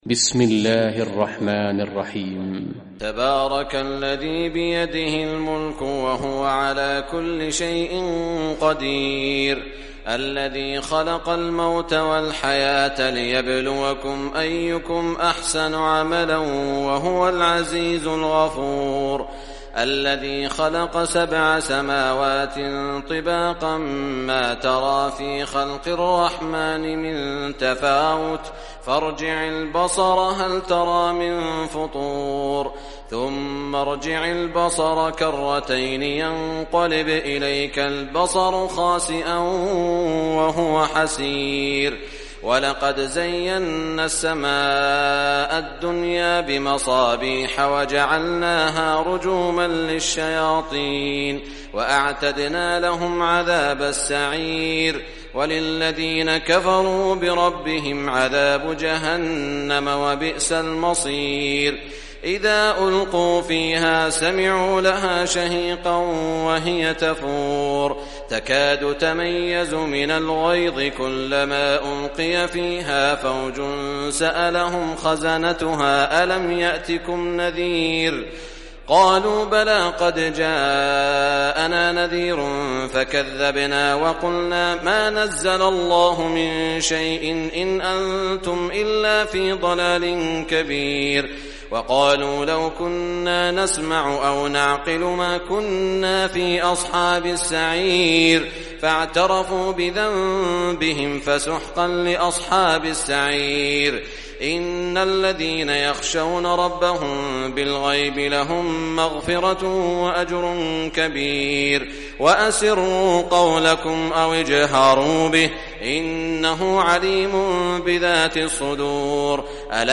Surah Mulk Recitation by Sheikh Shuraim
Surah Muk, listen or play online mp3 tilawat / recitation in Arabic in the beautiful voice of Sheikh Saud al Shuraim.